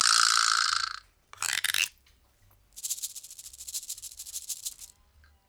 88-PERC-02.wav